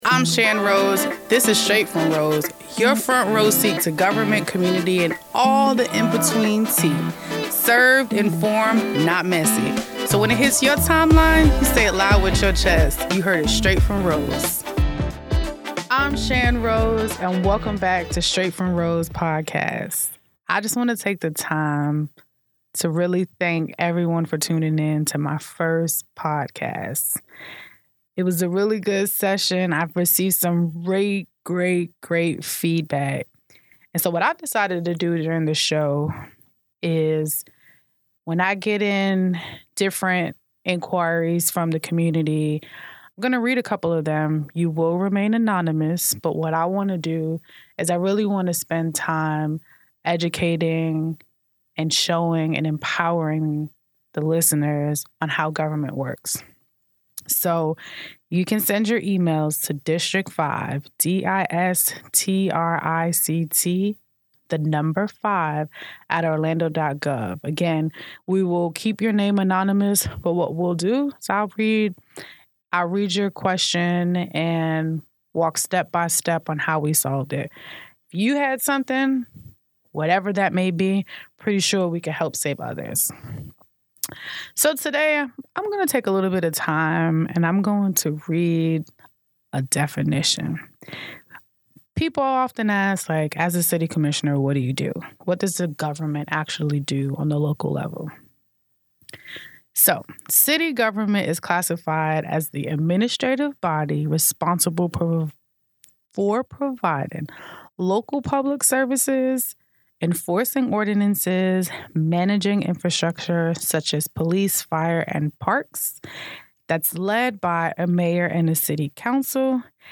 On this episode, Commissioner Shan Rose sits down for a deeper conversation about her role serving the residents of District 5 and what it really means to represent a community at the local government level. She breaks down the responsibilities of a city commissioner, how decisions made at city hall directly affect neighborhoods, and the challenges that come with advocating for communities that have often been overlooked. Commissioner Rose also speaks openly about the issues impacting the community today, including economic opportunity, neighborhood development, public safety, and access to resources.